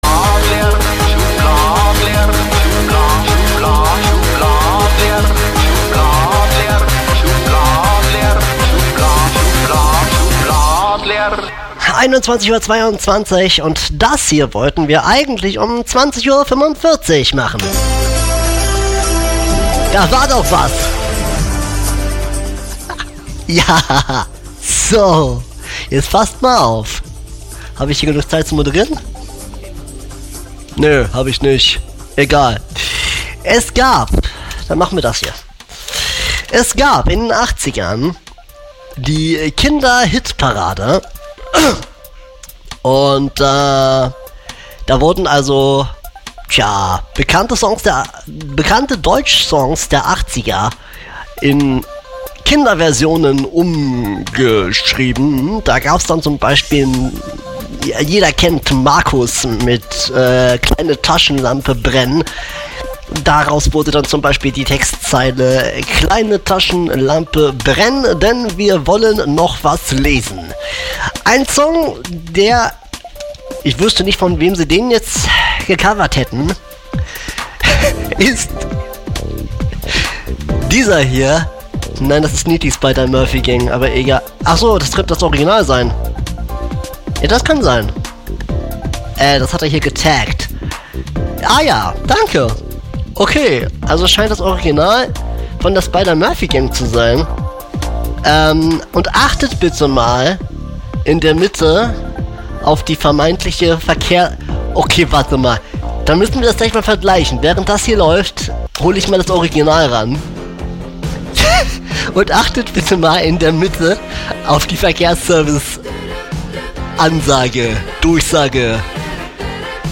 bayern3 verkehrsfunk mit ner bayrischen band.mp3